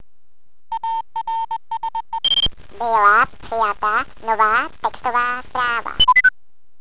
"Hlášení o příchozí SMS" - šmoulí hlas jako z vysílačky :-)